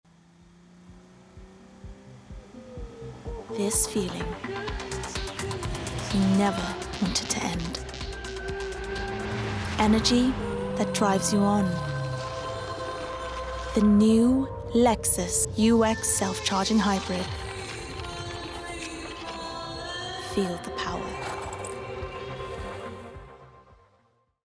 Versatile/Contemporary/Youthful
Lexus (British accent)